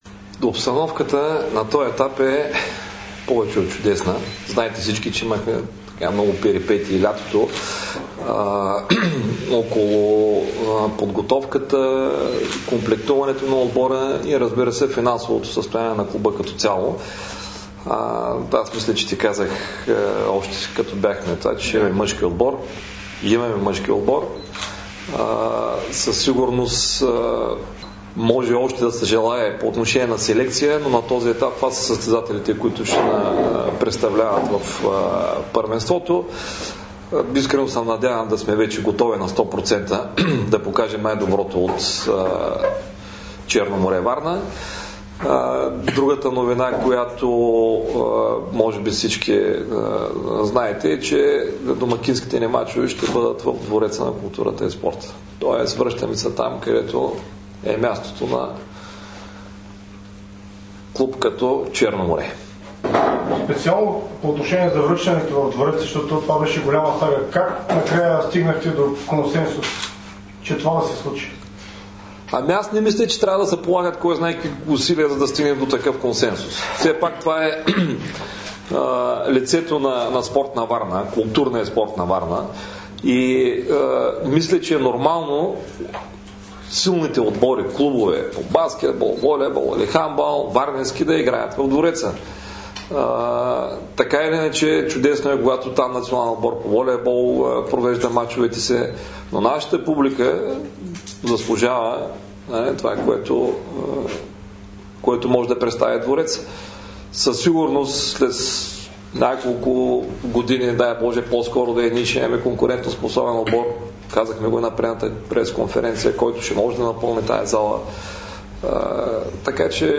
Чуйте цялото интервю с него в приложения звуков файл Новините на Dsport и във Facebook , Viber , YouTube , TikTok и Instagram !